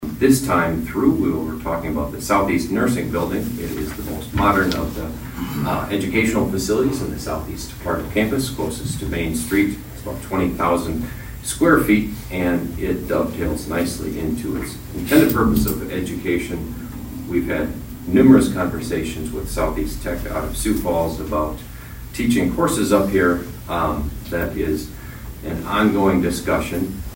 ABERDEEN, S.D.(HubCityRadio)- At Monday night’s Aberdeen City Council meeting, the council heard Mayor Travis Schaunaman about a possible purchasing agreement for the nursing building on the former Presentation College campus.